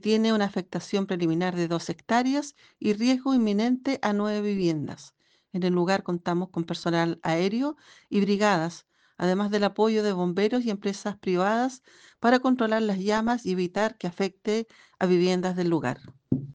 Por su parte, la directora de Conaf en La Araucanía, María Teresa Huentequeo, entregó más detalles de los recursos desplegados para el combate de las llamas.